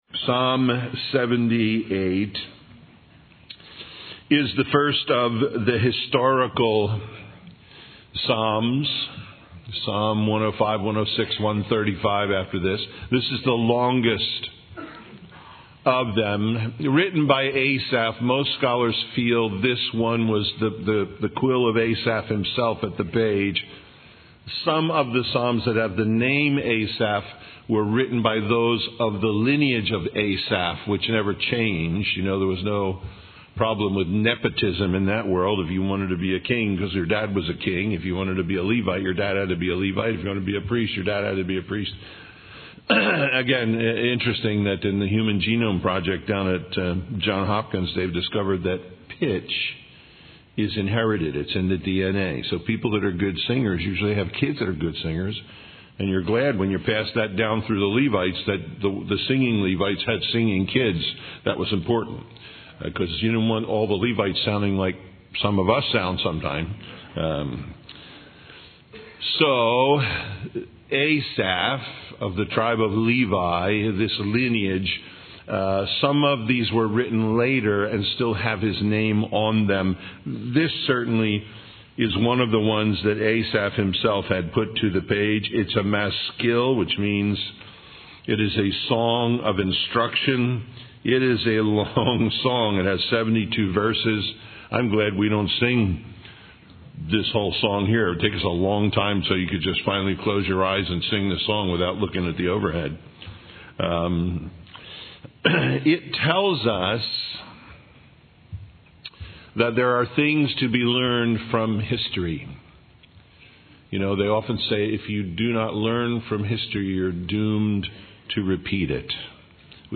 Psalms 78:1-78:72 Listen Download Original Teaching Email Feedback 78 Give ear, O my people, to my law: incline your ears to the words of my mouth.